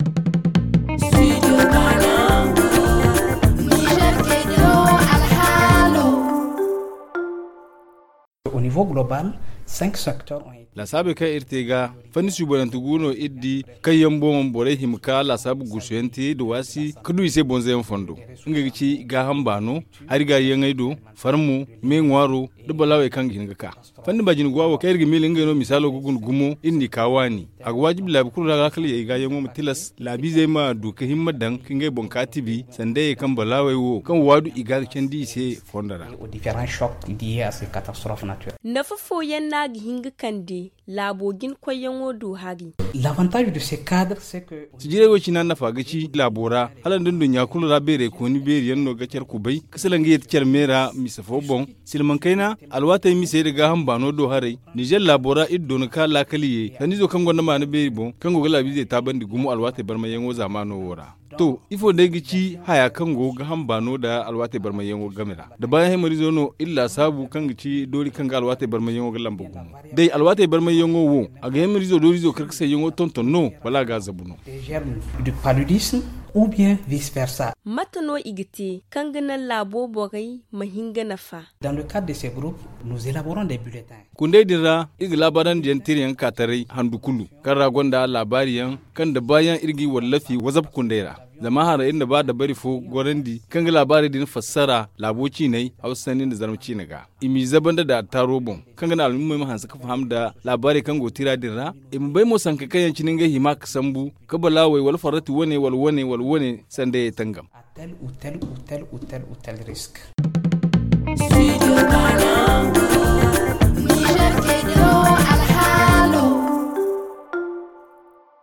Le magazine en zarma